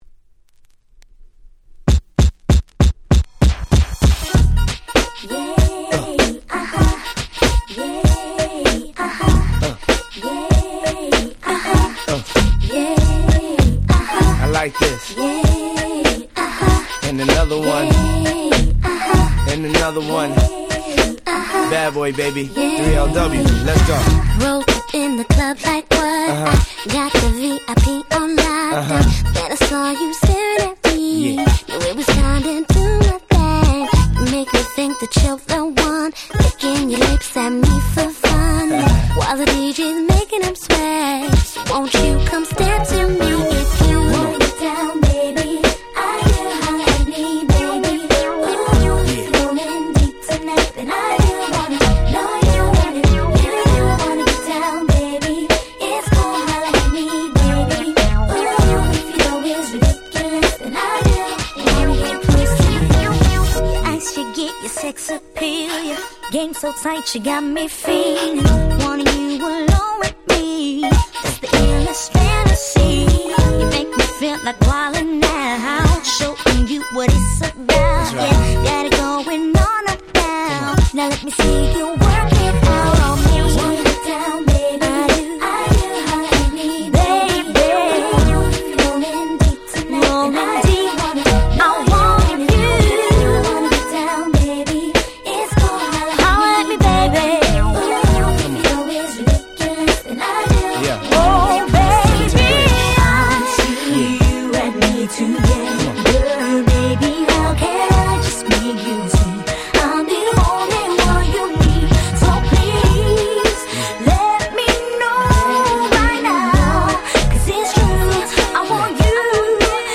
02' Smash Hit R&B !!
Very Nice Hip Hop Soul !!